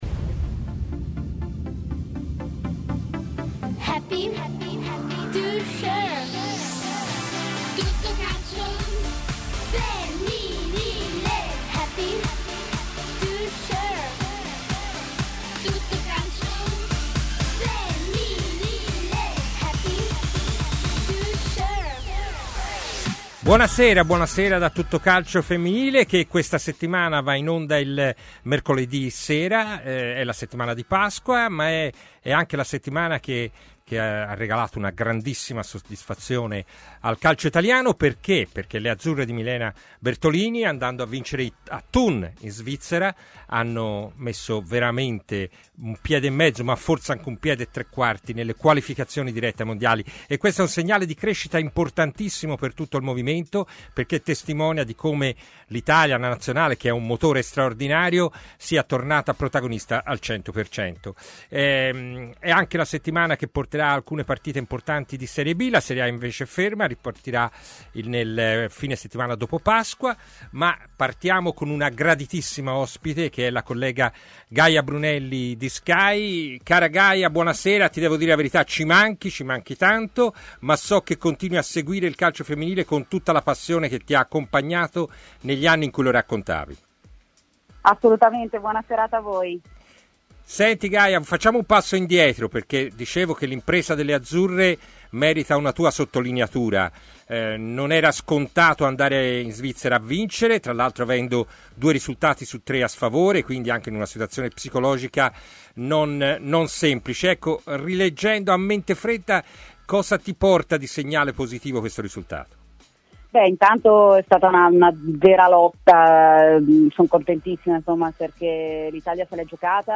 Ospiti telefonici